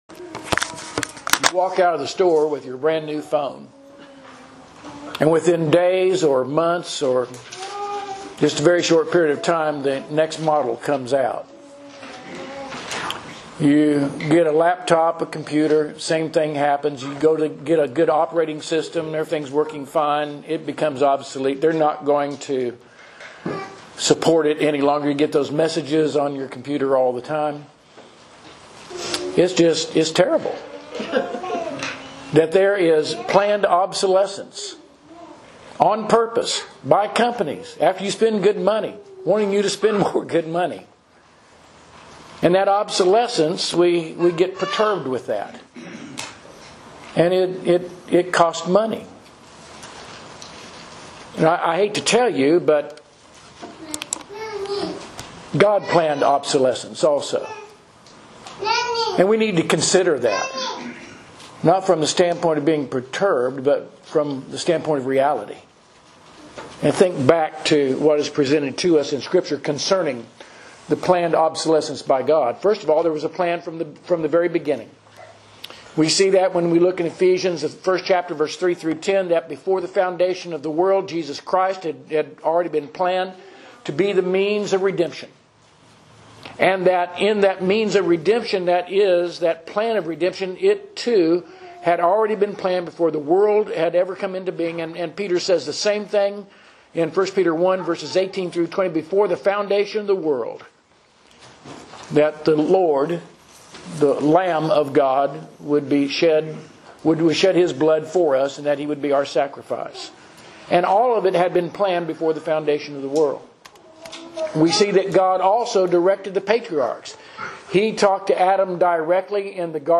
Sermons – Page 13 – South Loop church of Christ